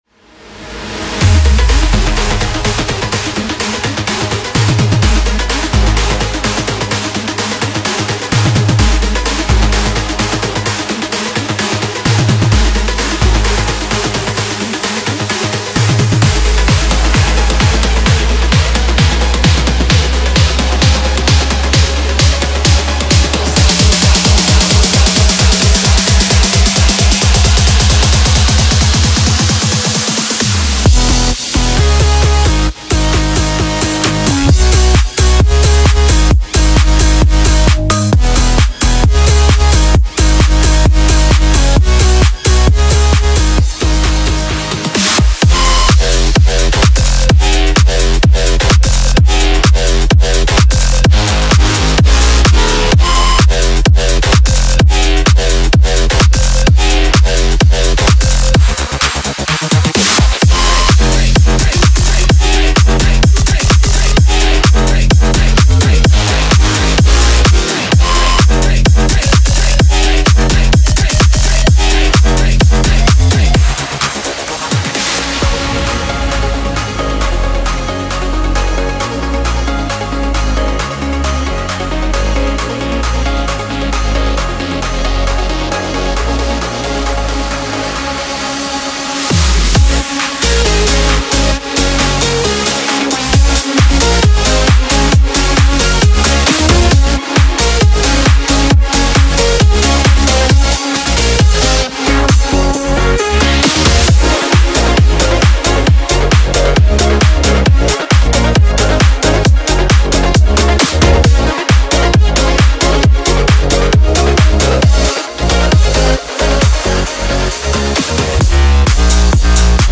它拥有浑厚强劲的低音和丰富的律动，每个预设都经过精心调校，在保持清晰、现代且即刻适用于夜店的同时，力求达到最佳冲击力